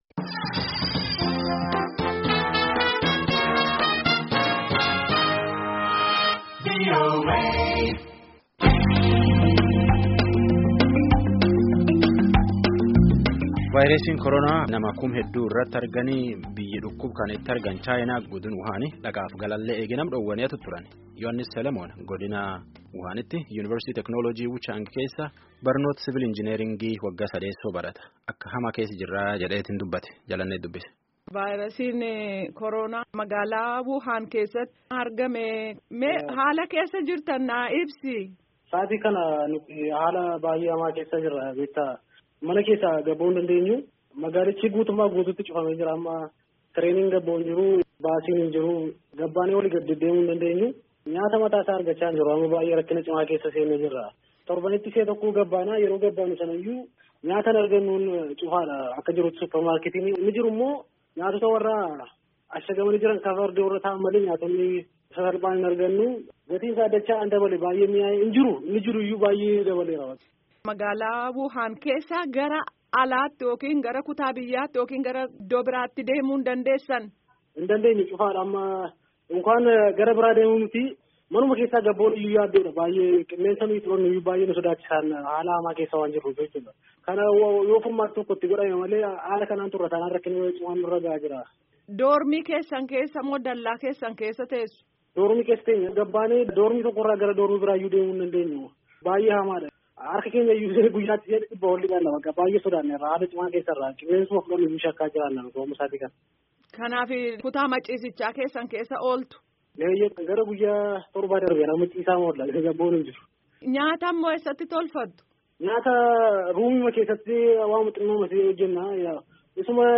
Gaaffii Deebii Gaggeefame Caqasaa